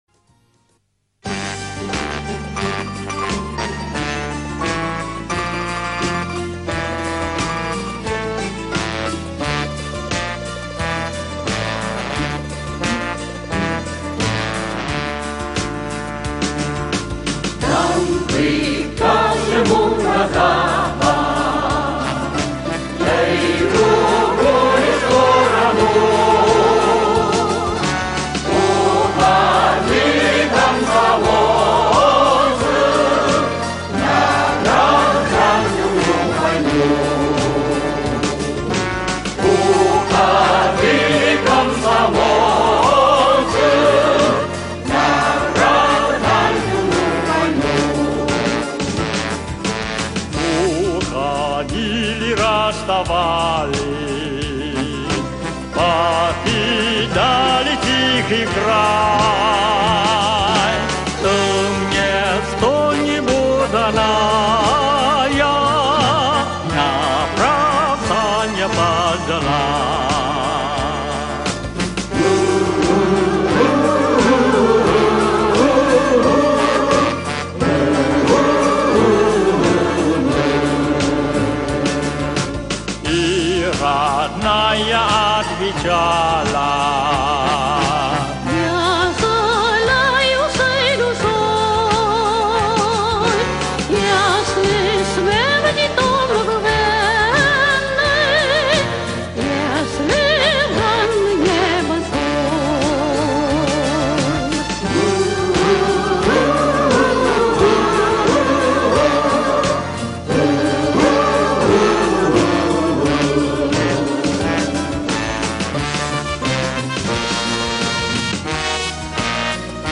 Вьетнамская версия